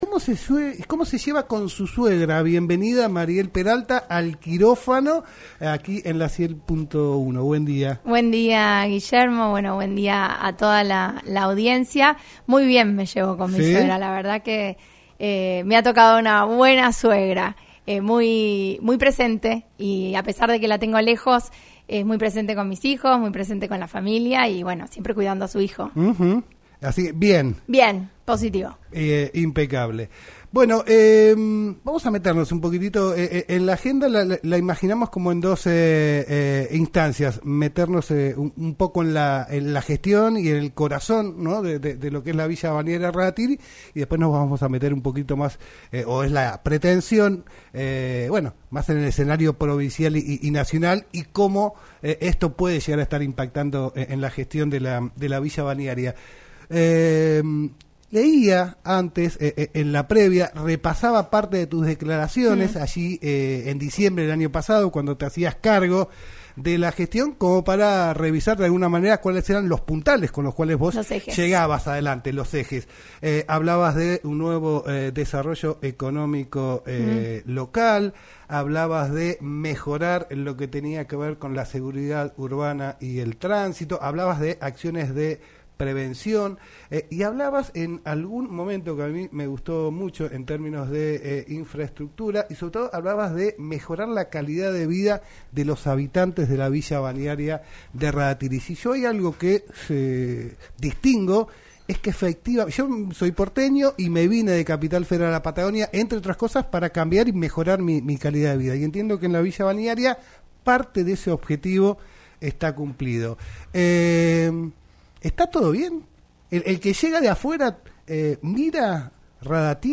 Mariel Peralta, intendente de Rada Tilly, visitó los estudios de LaCienPuntoUno para hablar en "El Quirófano" sobre sus primeros diez meses de gestión, los objetivos cumplidos desde que asumió y lo que le falta a la ciudad para seguir creciendo.